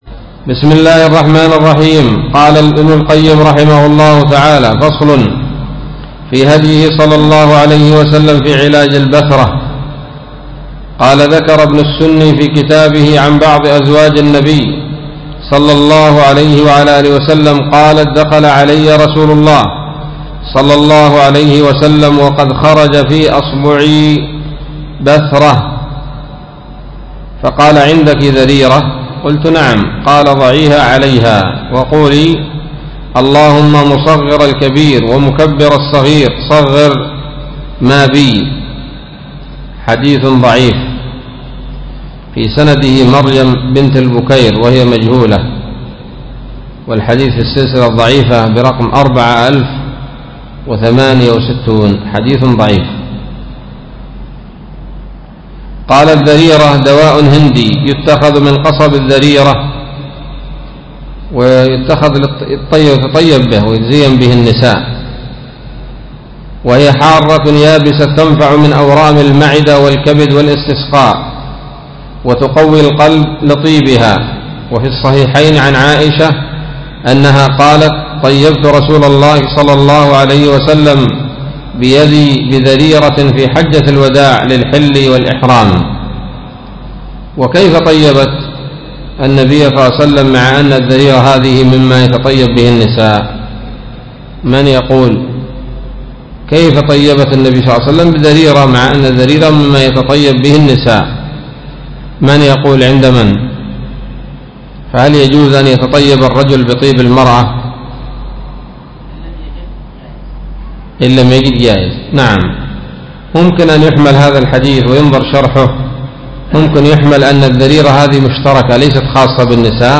الدرس الحادي والثلاثون من كتاب الطب النبوي لابن القيم